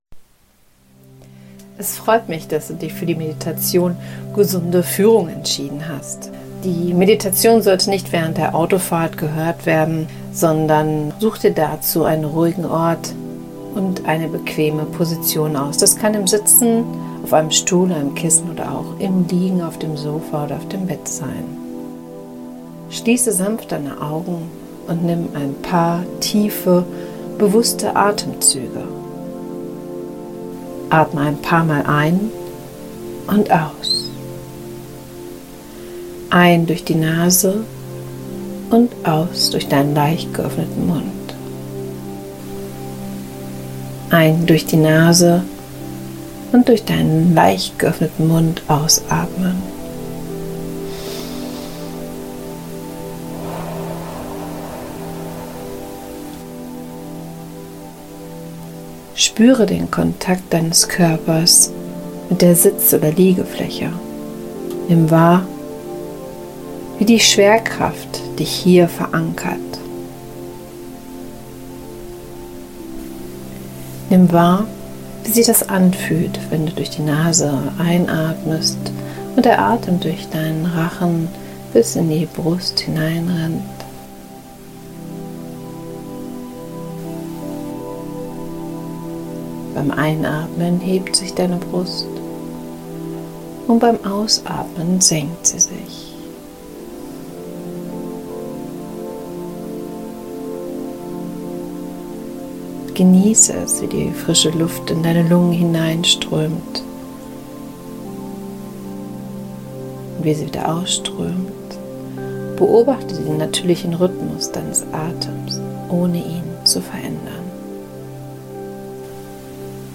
HealthLeadership_Meditation.mp3